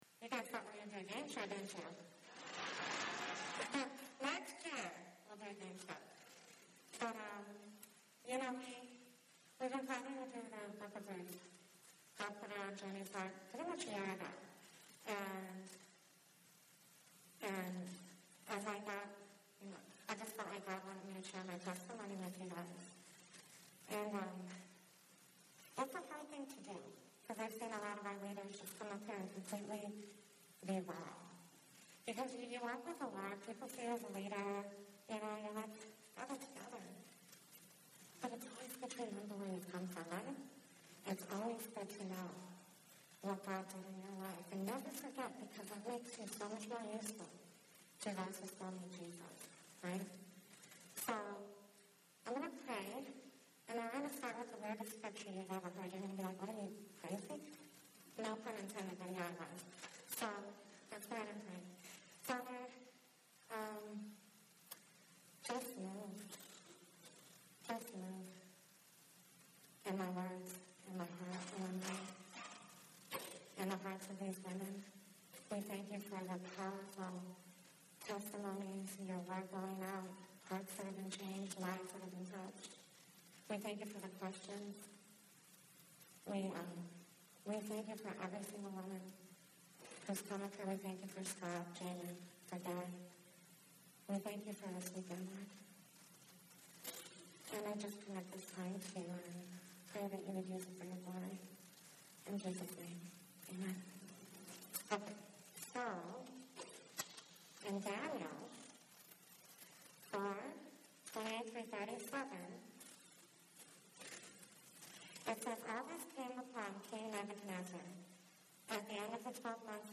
Women's Retreat 2019: Ruth A True Story